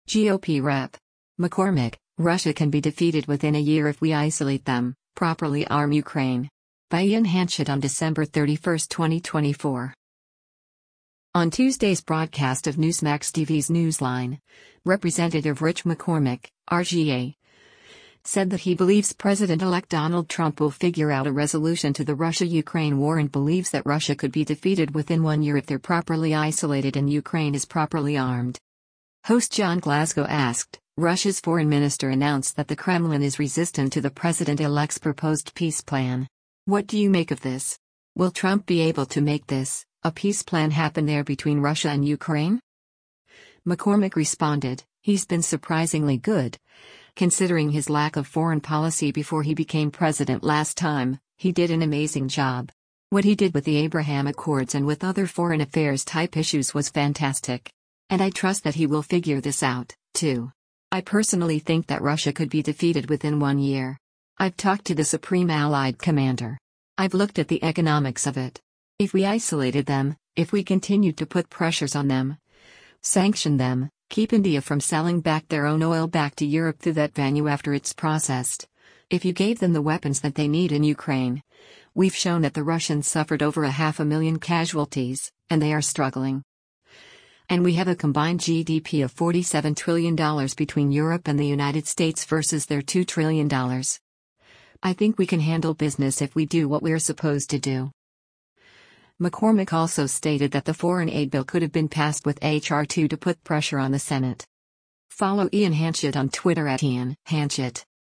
On Tuesday’s broadcast of Newsmax TV’s “Newsline,” Rep. Rich McCormick (R-GA) said that he believes President-Elect Donald Trump will figure out a resolution to the Russia-Ukraine war and believes that Russia “could be defeated within one year” if they’re properly isolated and Ukraine is properly armed.